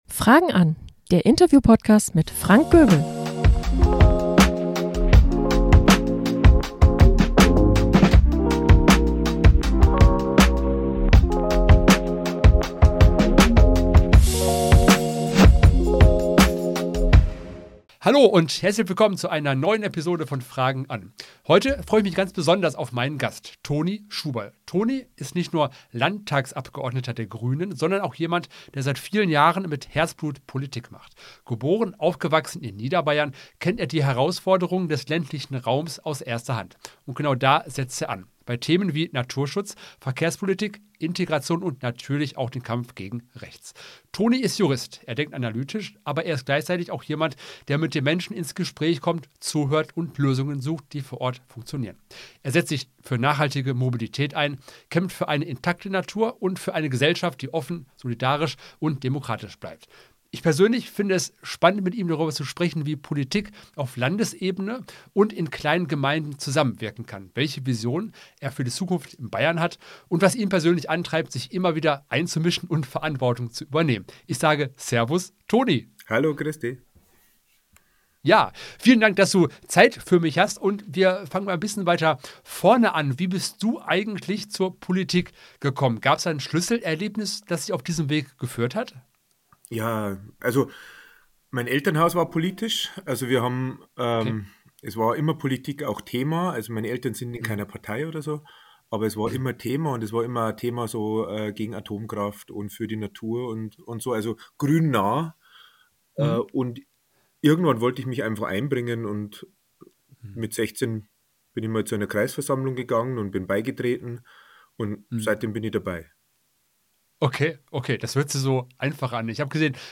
Beschreibung vor 4 Monaten Politik, Hochwasserschutz & Zivilcourage – Toni Schuberl im Gespräch Beschreibung: In dieser Folge spreche ich mit Toni Schuberl, Landtagsabgeordneter der Grünen, über seinen Weg in die Politik, aktuelle Herausforderungen Bayerns und wie man Demokratie aktiv schützt.